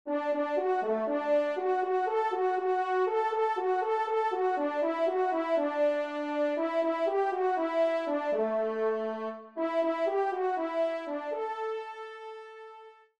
Trompe (Solo, Ton simple)